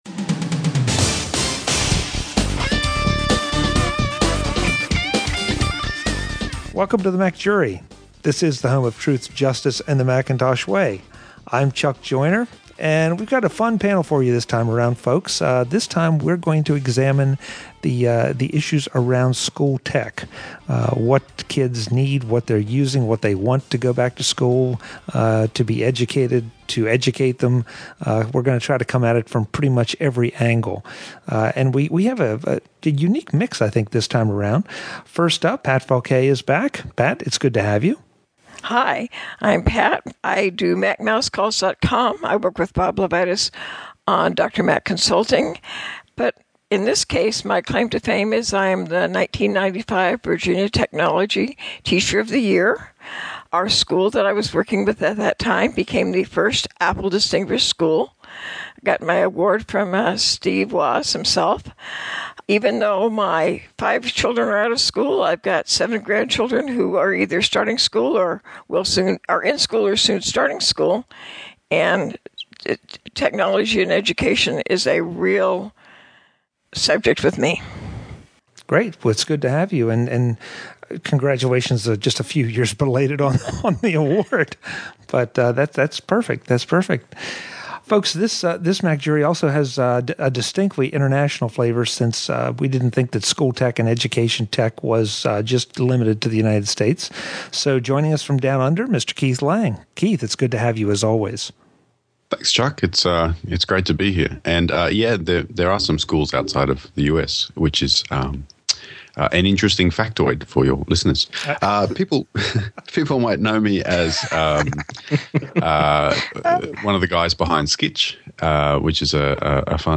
I was part of an amazing MacJury-podcast panel discussion yesterday on technology in education.